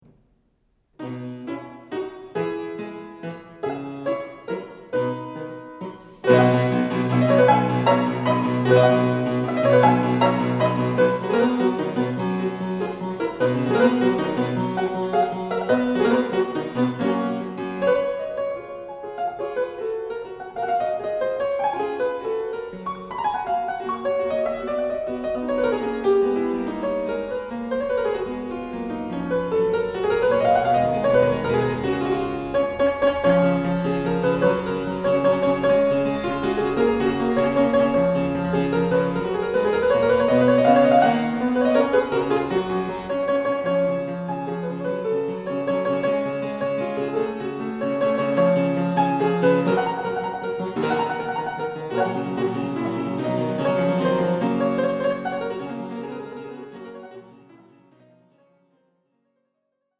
Registration: October 2008, Basel.